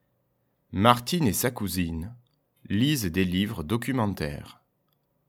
Et enfin, voici les deux phrases de la Twictée 14, lues par le maître pour écrire tout seul !